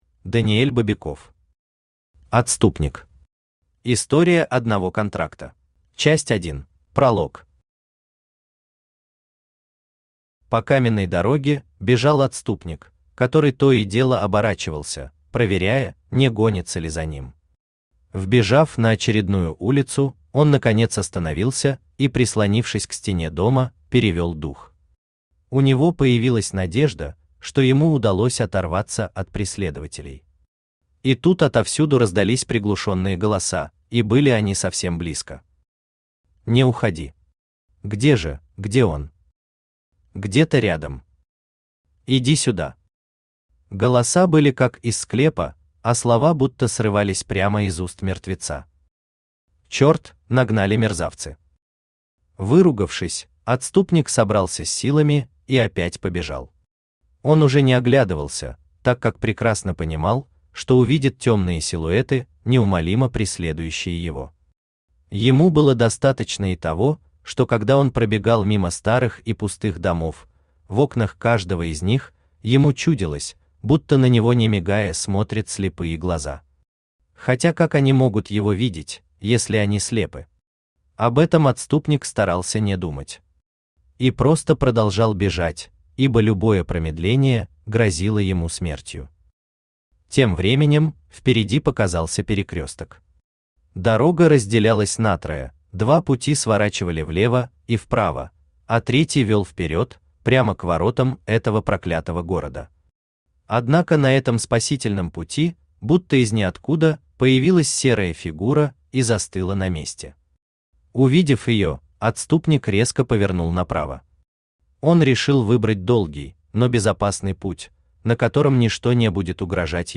Аудиокнига Отступник. История одного контракта | Библиотека аудиокниг
История одного контракта Автор Даниэль Григорьевич Бобяков Читает аудиокнигу Авточтец ЛитРес.